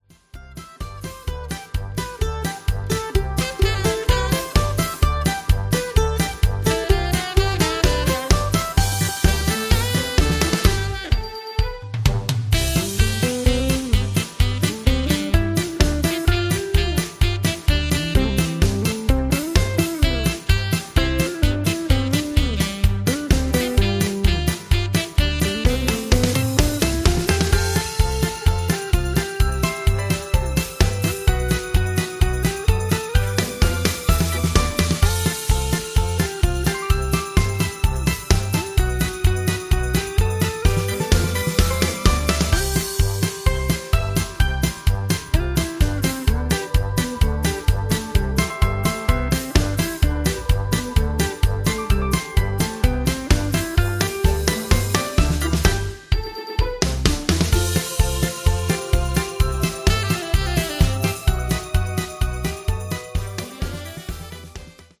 (Instrumental)
Singing Calls